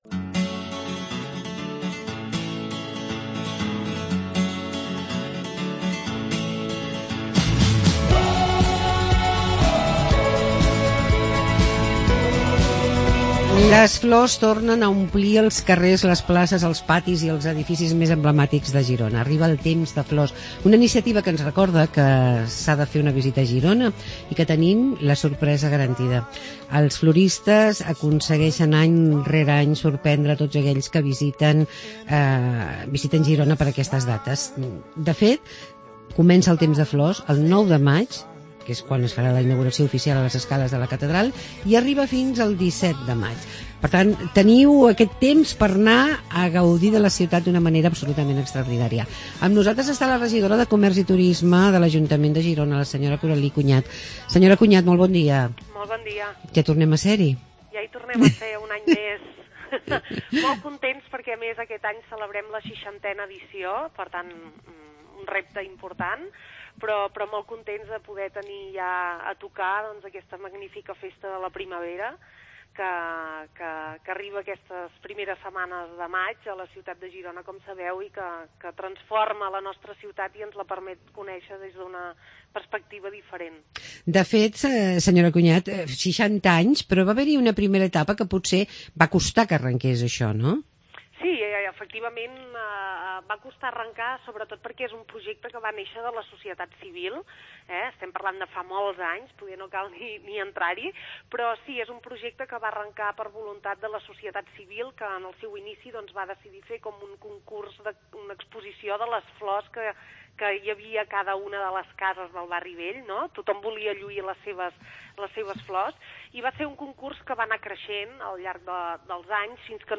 La Coralí Cunyat, regidora de Comerç i Turisme de l' Ajuntament de Girona ens parla de Temps de Flors